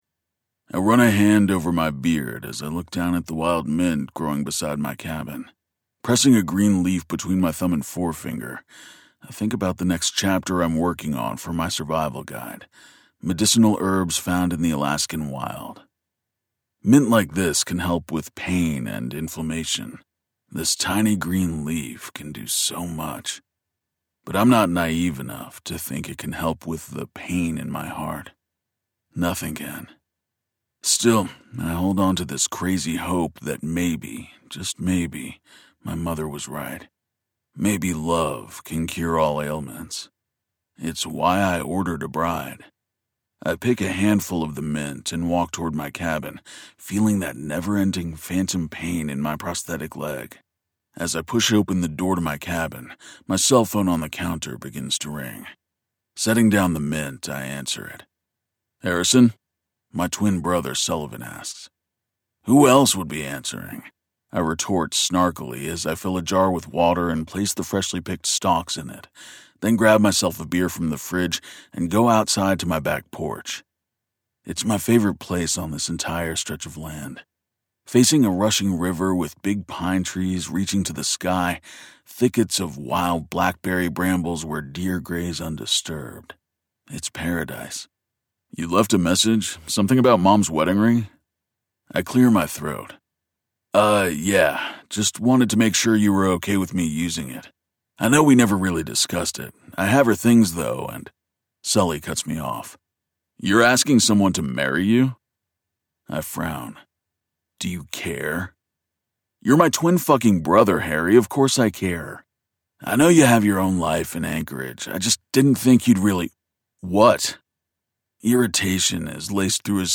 Related Audiobooks